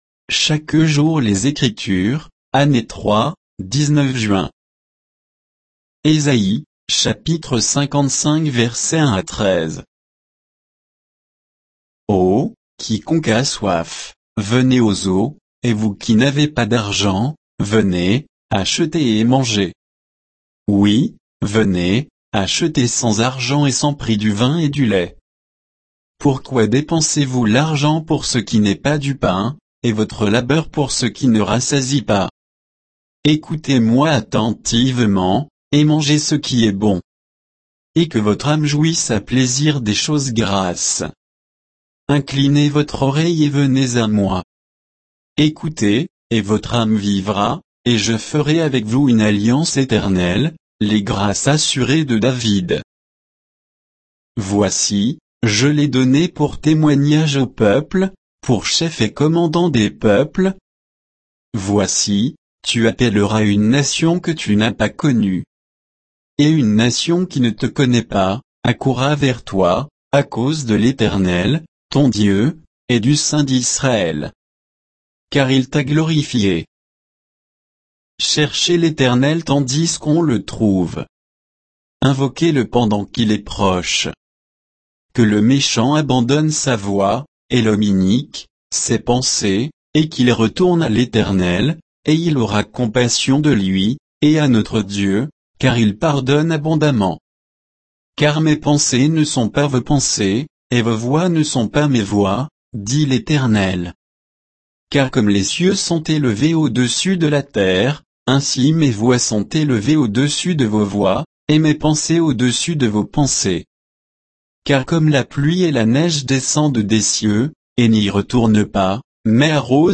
Méditation quoditienne de Chaque jour les Écritures sur Ésaïe 55, 1 à 13